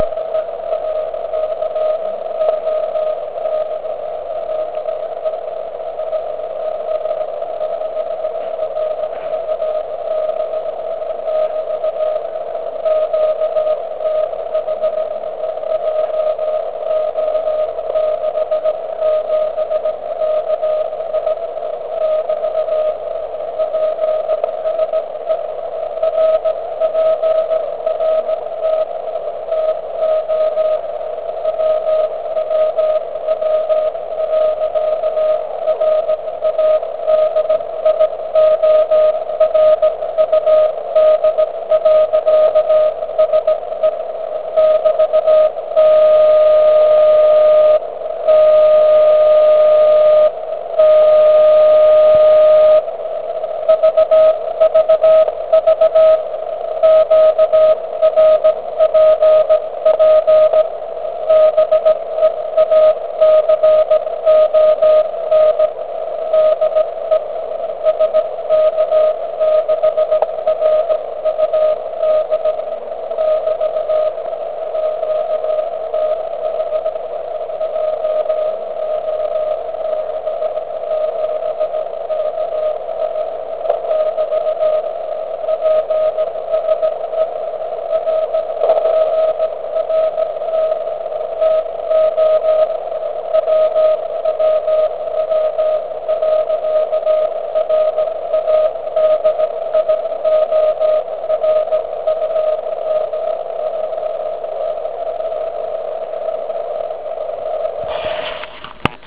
Důkazem nechť je následující porovnání poslechu majáku SK6RUD ve večerních hodinách na kmitočtu 3542,6 KHz. Maják má 500mW a vysílá do FD4 ANT.
SK6RUD srovnávání TCVR (*.wav 400 KB)
FT1000 samozřejmě musí zvítězit, ale pokud si uvědomíte, že měla zapnutý NR, dále DSP a úzký filtr 250 HZ a je vybavena speciálním Roofing filtrem, pak jen za tento filtr se dá více peněz, než za celý ATS-3B. Přesto ten rozdíl je dosti malý.